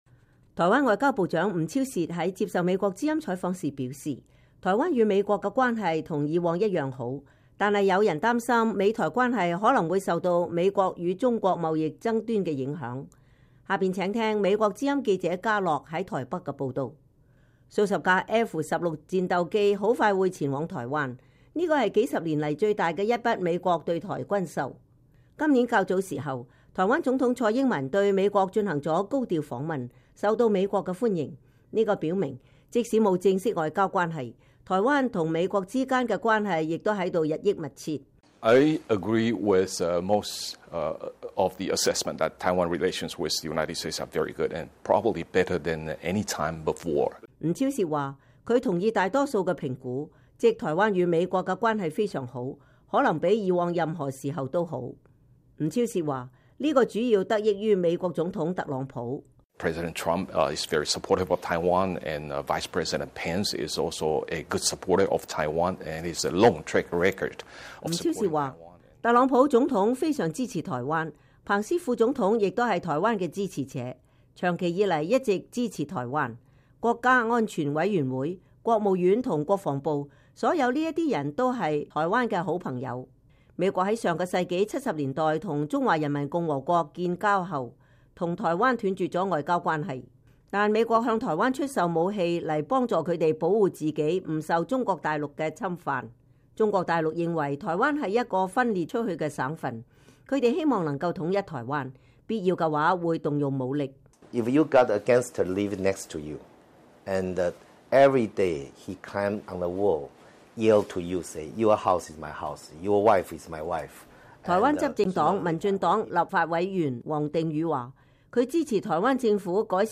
台灣外交部長吳釗燮在接受美國之音採訪時表示，台灣與美國的關係和以往一樣好。但有人擔心美台關係可能會受到美國與中國貿易爭端的影響。